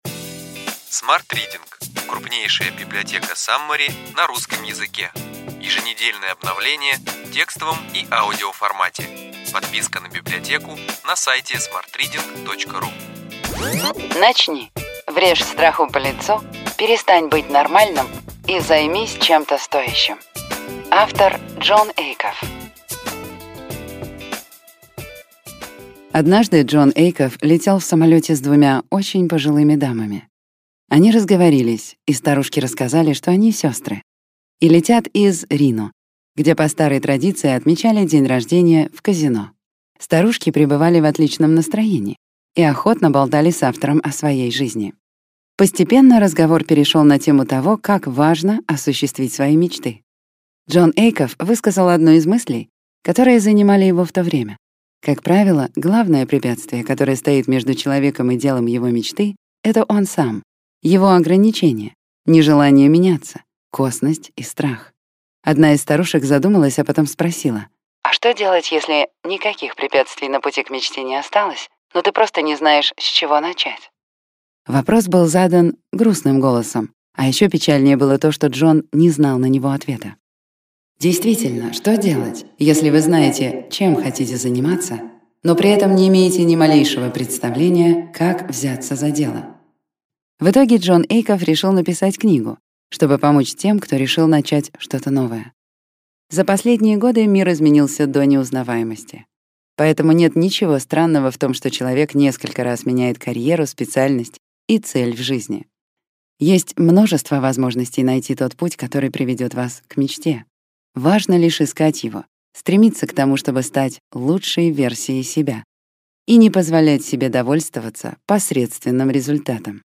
Аудиокнига Ключевые идеи книги: Начни.